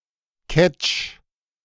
Ketsch (German pronunciation: [kɛtʃ]